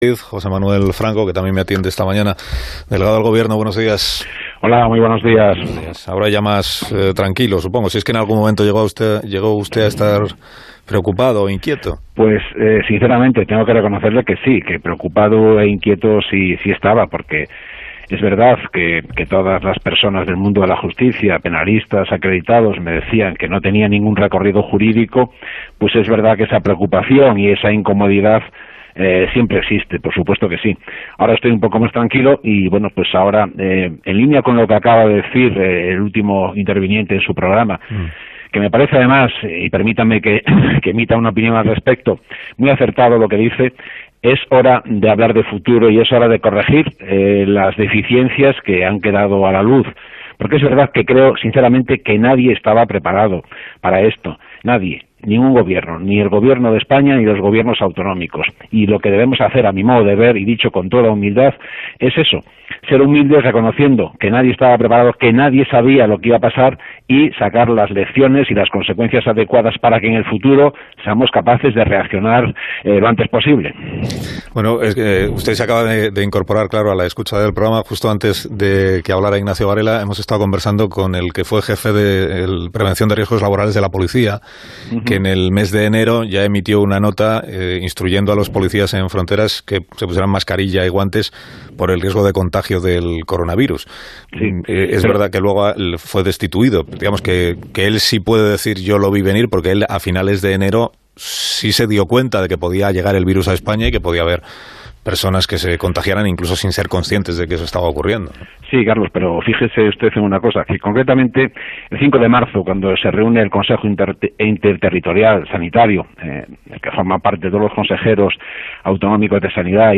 En una entrevista en el programa 'Más de Uno' de Onda Cero, Franco ha explicado que llegó a estar preocupado por su imputación en la causa del 8-M pese a que los penalistas le decían que el proceso no tenía recorrido.